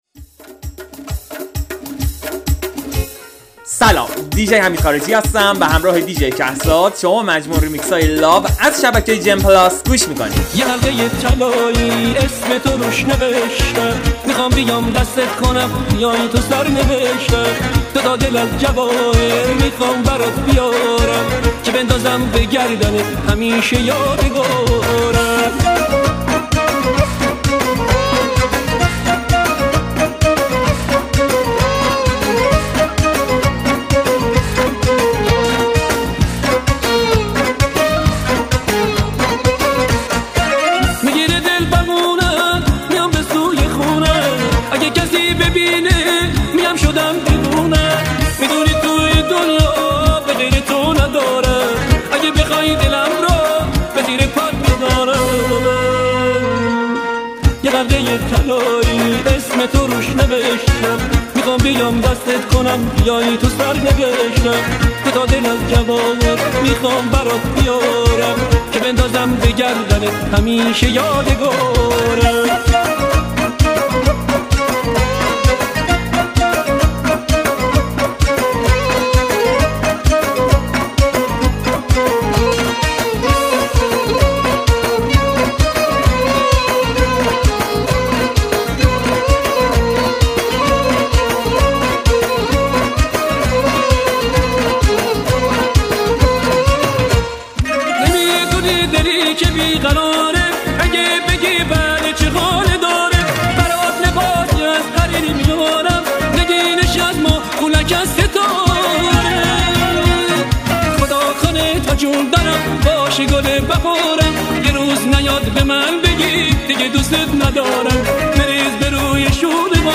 دانلود ریمیکس طولانی آهنگ های شاد به یادماندهی دهه ۶۰ و ۷۰